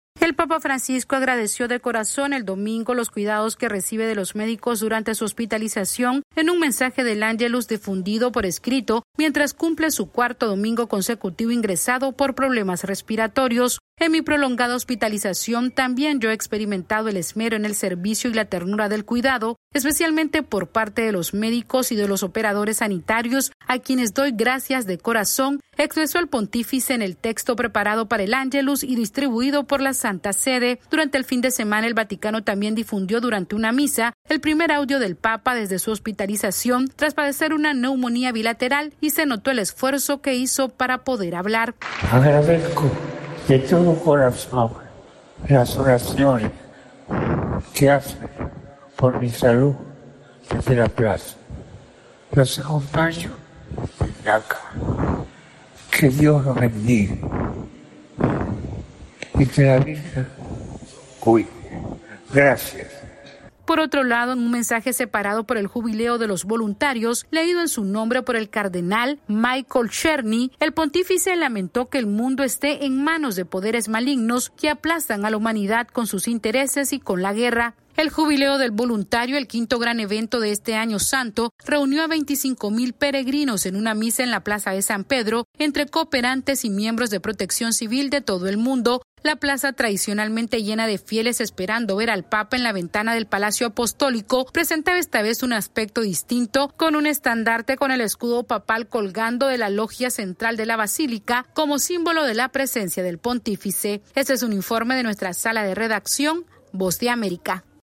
El papa Francisco estuvo ausente por cuarto domingo consecutivo del Ángelus pero envió un mensaje de agradecimiento a los médicos que lo cuidan y la comunidad católica que reza por su salud. Este es un informe de nuestra Sala de Redacción....